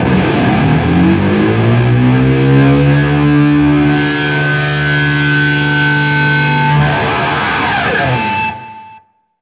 DIVEBOMB15-R.wav